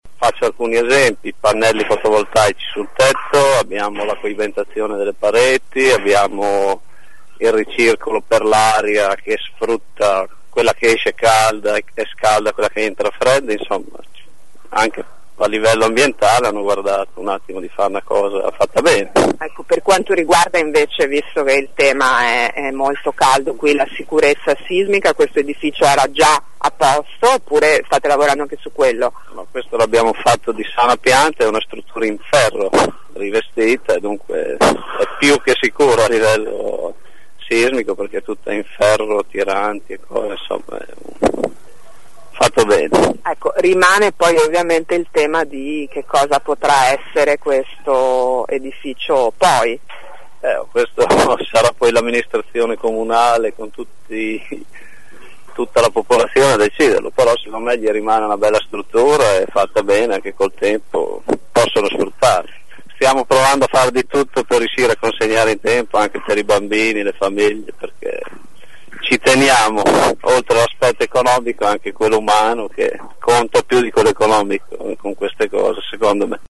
Ecco alcune delle voci raccolte questa mattina dai nostri inviati.